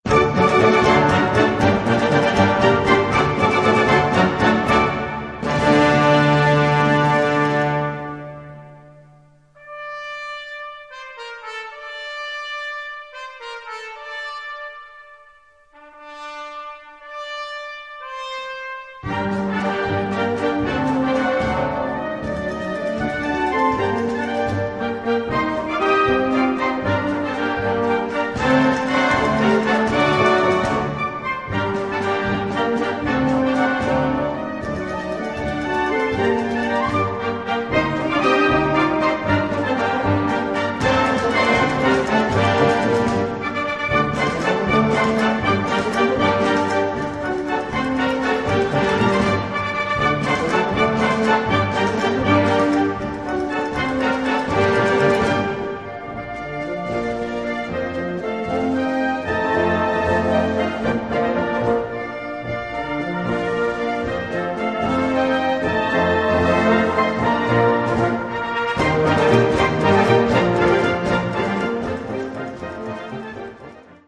Gattung: Konzertstück
A4 Besetzung: Blasorchester Zu hören auf